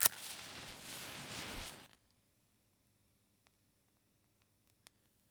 Matches 04.wav